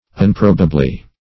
Unprobably \Un*prob"a*bly\, adv. [Pref. un- not + probably.]